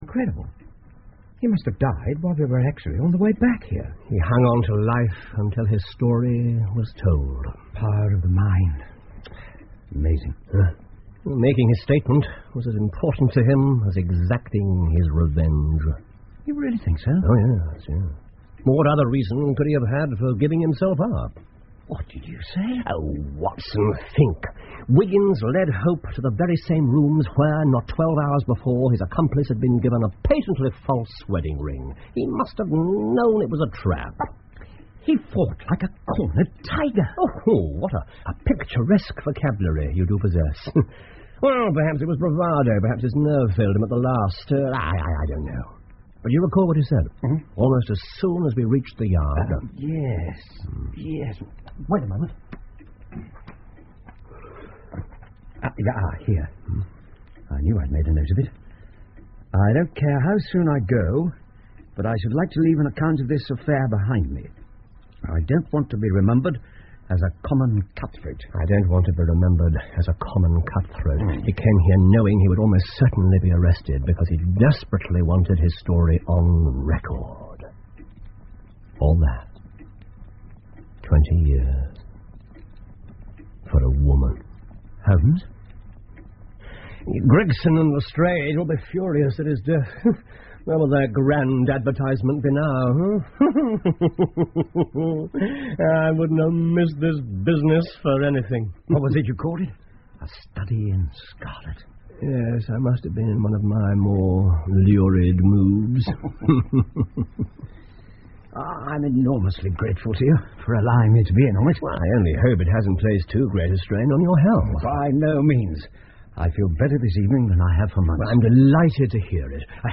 福尔摩斯广播剧 A Study In Scarlet 血字的研究 22 听力文件下载—在线英语听力室